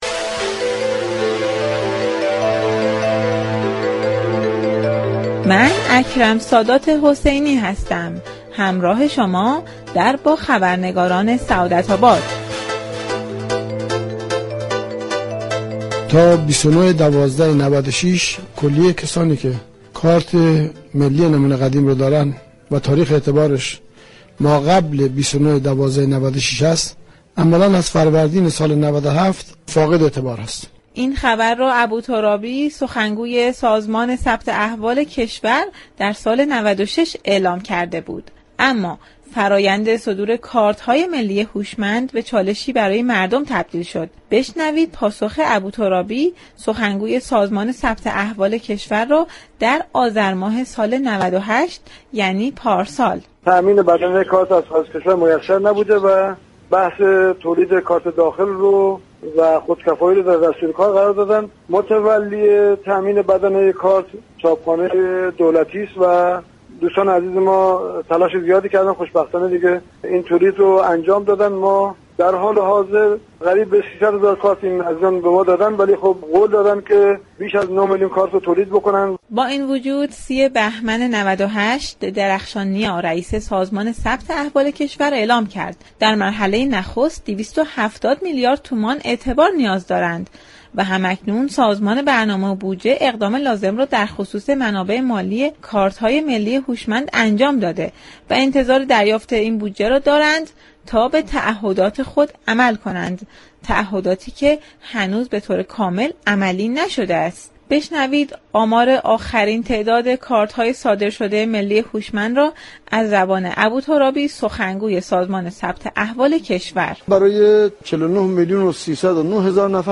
به گزارش پایگاه اطلاع رسانی رادیو تهران، محمود ملكوتی خواه در گفتگو با برنامه سعادت آباد با تاكید بر اینكه طرح تعویض كارت های ملی با شكست مواجه نشده گفت: این طرح با فراز و نشیب هایی مواجه بوده است.